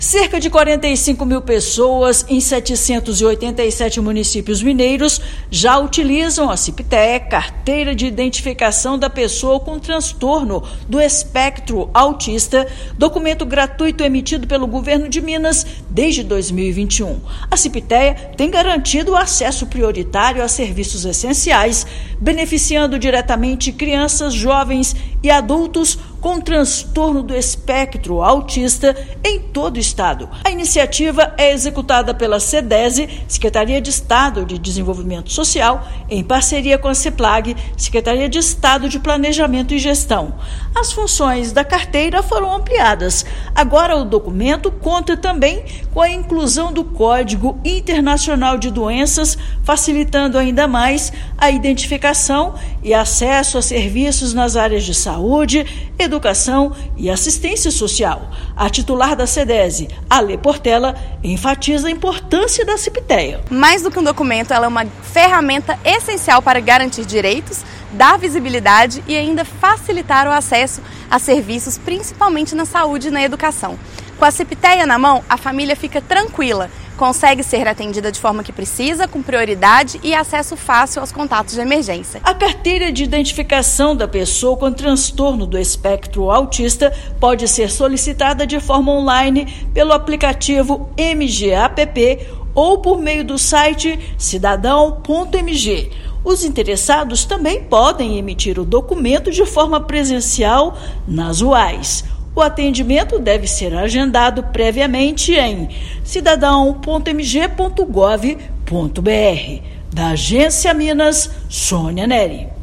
Mais de 45 mil pessoas em 787 municípios já possuem o documento gratuito emitido pelo Estado. Ouça matéria de rádio.